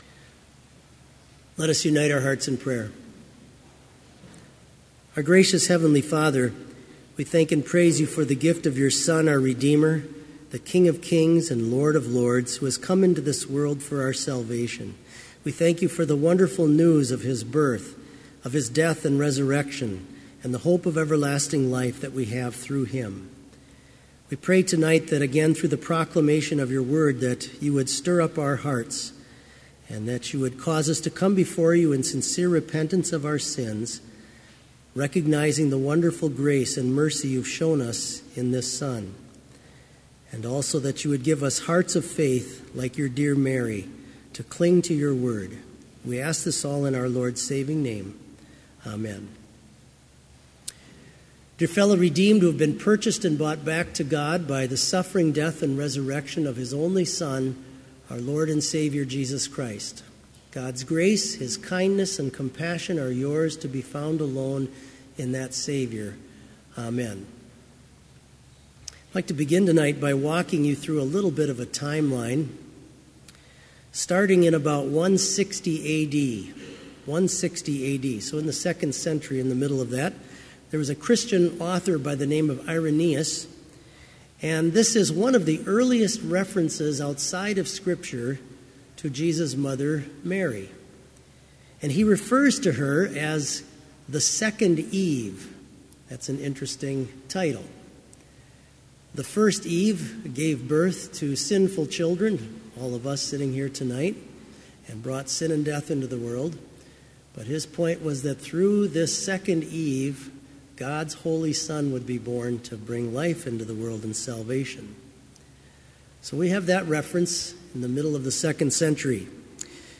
Complete service audio for Evening Vespers - December 6, 2017